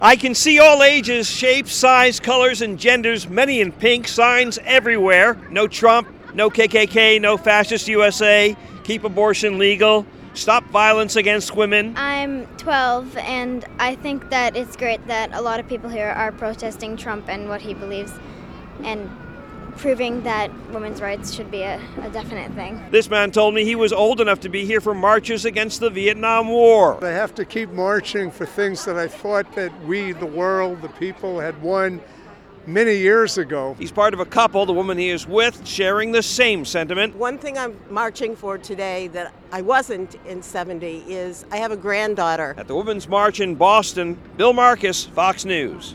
FILED THIS REPORT.